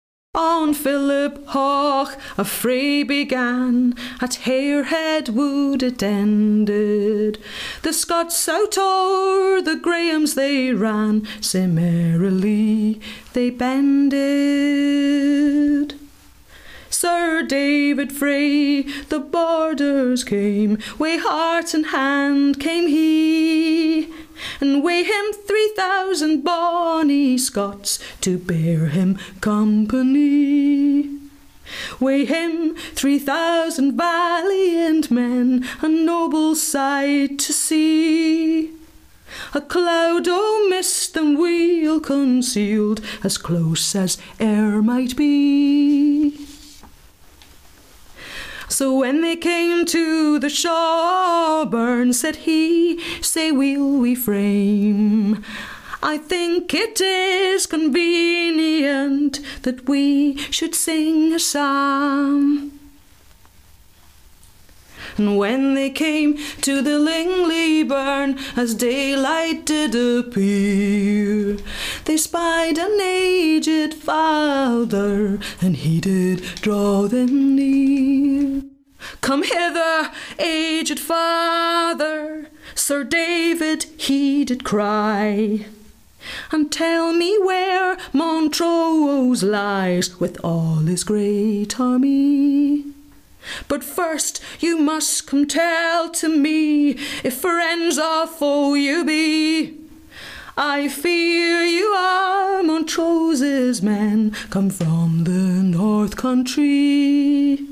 the-ballad-of-philiphaugh-with-reverb-edit.mp3